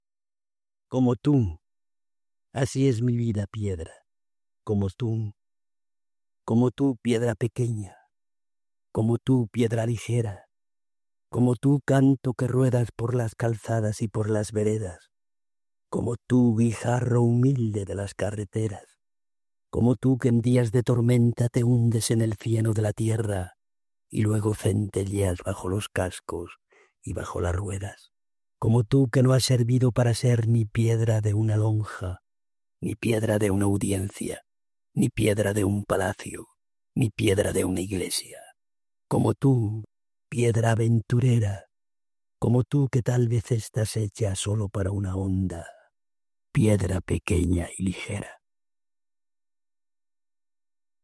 Audio: León Felipe